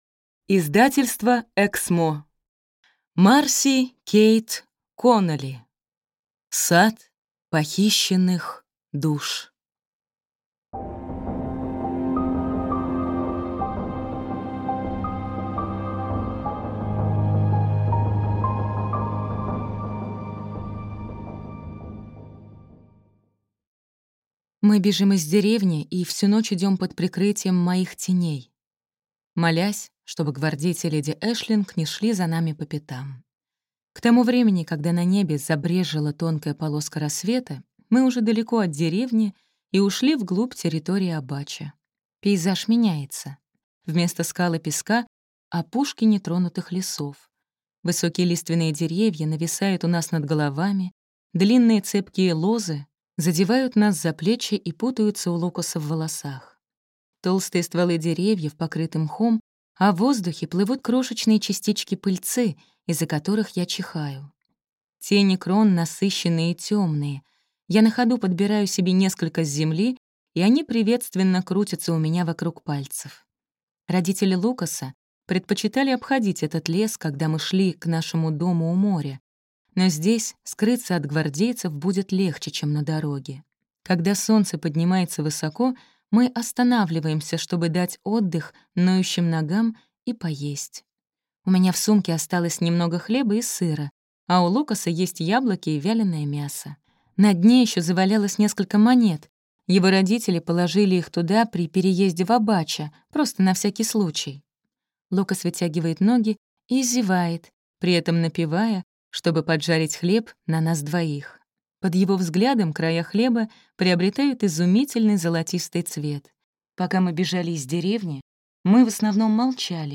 Аудиокнига Сад похищенных душ | Библиотека аудиокниг